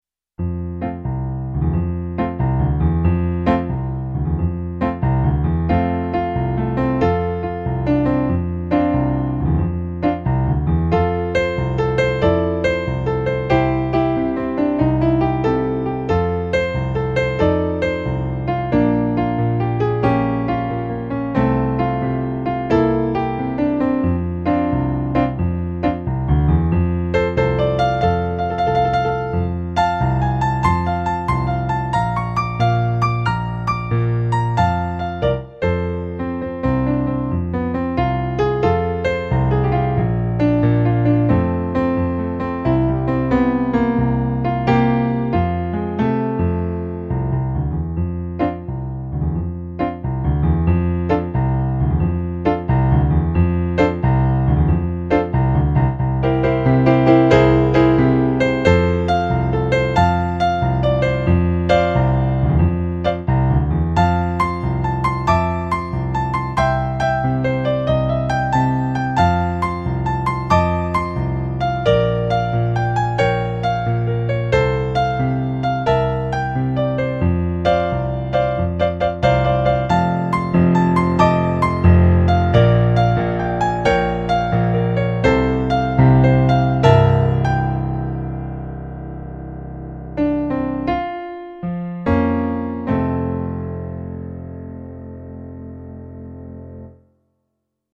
contains eight piano solo arrangements.
gospel blues remix